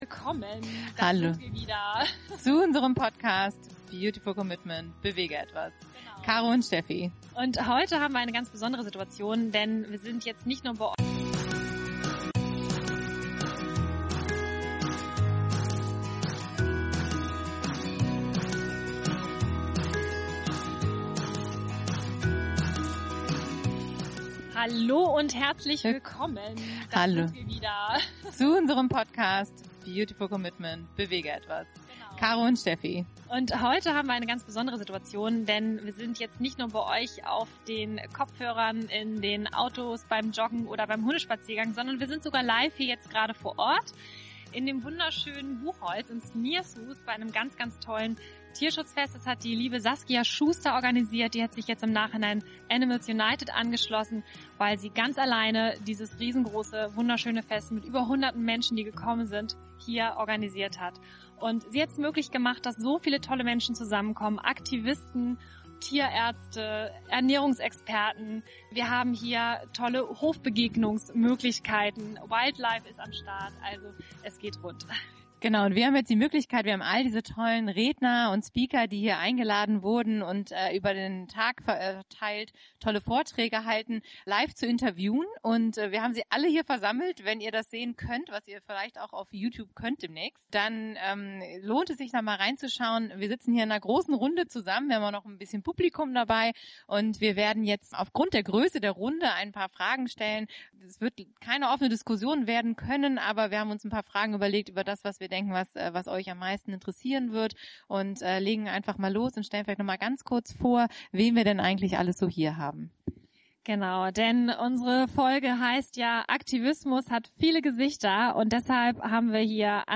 In unserer ersten Live Podcast-Folge präsentieren wir dir ein Interview mit einer Expertenrunde u. a. zu den Themen Umweltschutz, Tierrecht, Ernährung, Milchindustrie und Artenschutz. Du erhältst Einblicke in die verschiedenen Formen des Aktivismus sowie Anregungen wie du dich individuell engagieren kannst.